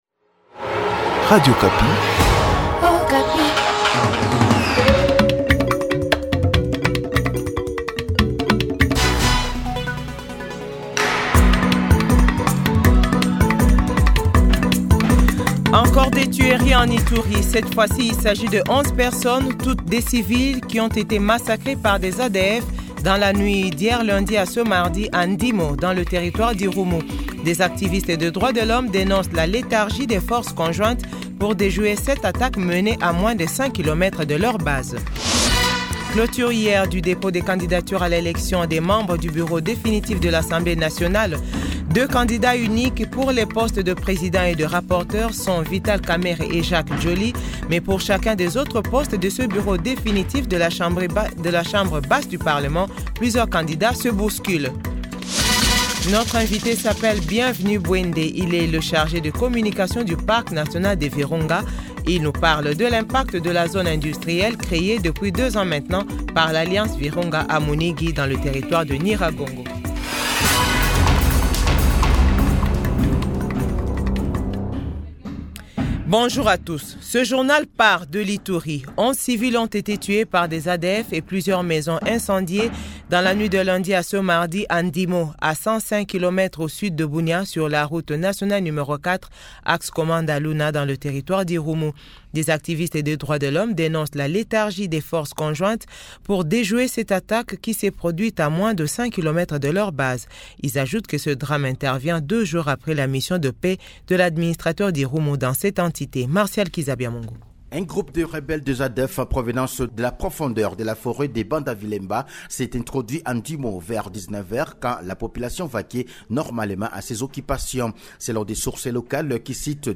JOURNAL FRANCAIS 12H00